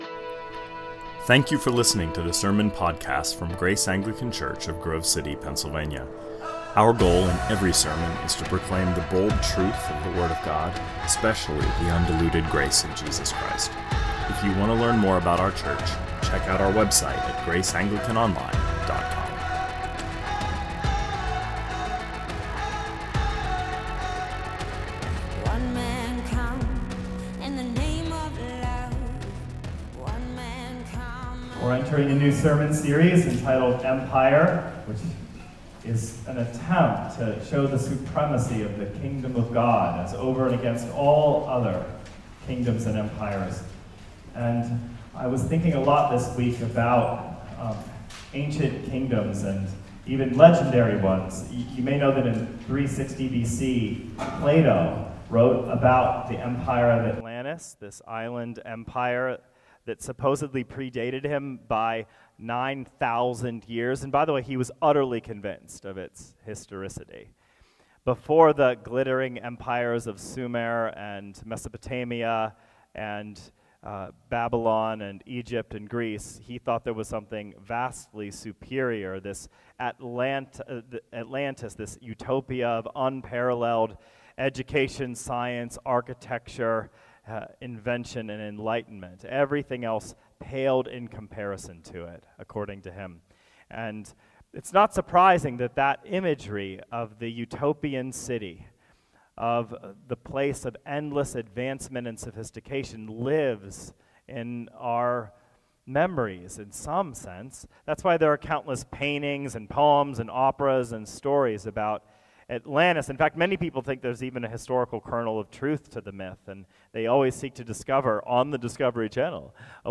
2024 Sermons Empire I: The Genesis Kingdom -Genesis 1 & 2 Play Episode Pause Episode Mute/Unmute Episode Rewind 10 Seconds 1x Fast Forward 30 seconds 00:00 / 31:42 Subscribe Share RSS Feed Share Link Embed